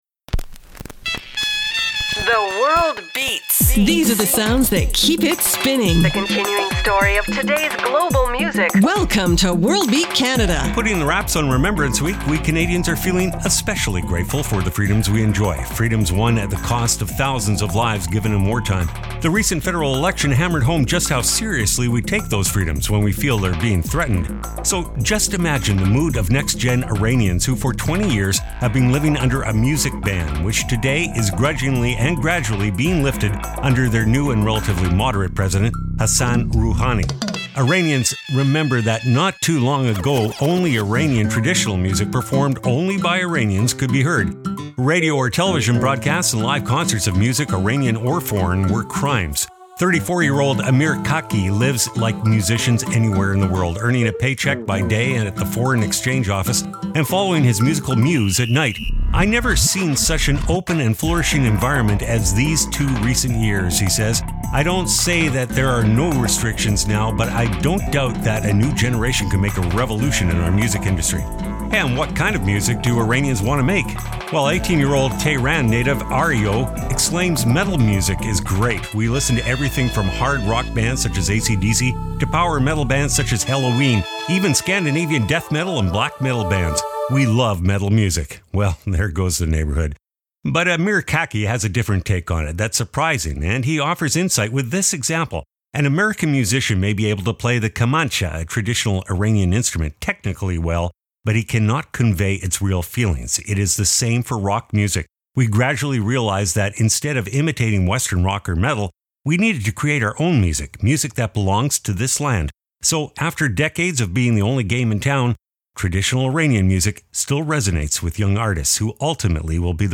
exciting conntemporary global music alternative to jukebox radio
New Bhangra/Balkan brass